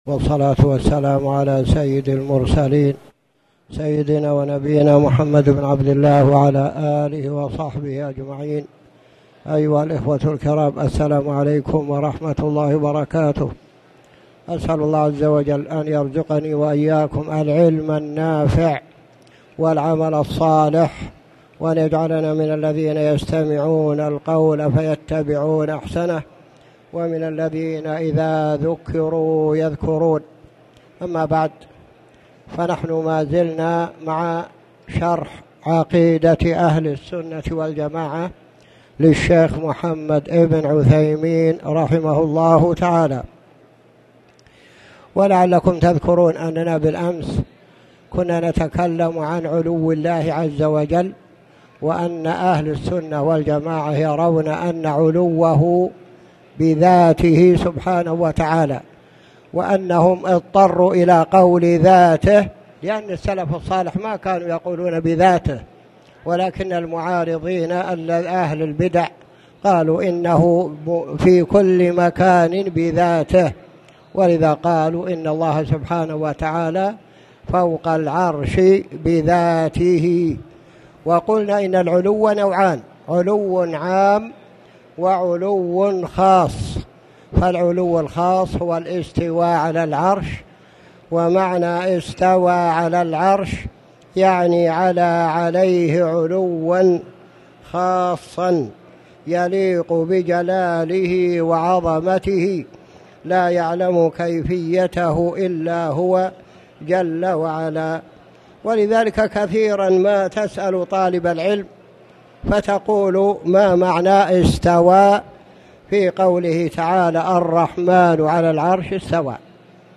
تاريخ النشر ٢٨ شعبان ١٤٣٨ هـ المكان: المسجد الحرام الشيخ